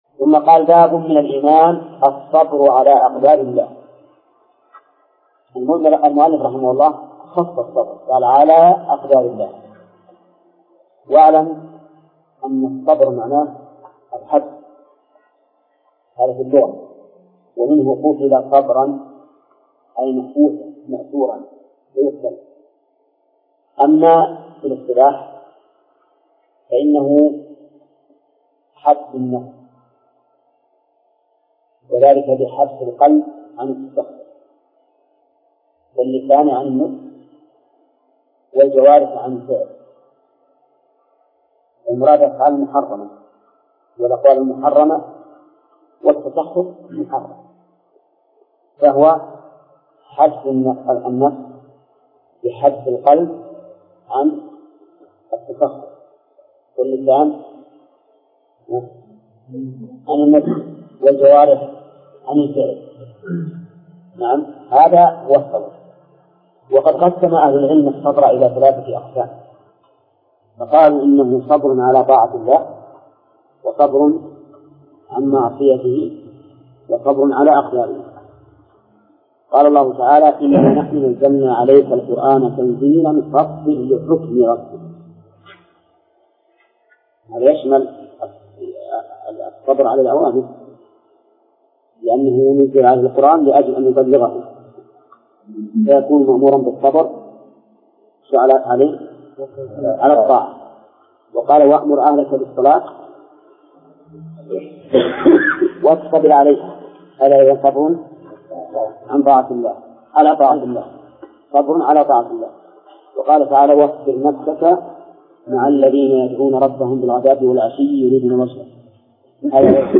درس (34) / المجلد الثاني : من صفحة: (109)، قوله: (باب من الإيمان بالله الصبر ..)..، إلى صفحة: (124)، قوله: (باب ما جاء في الرياء ..).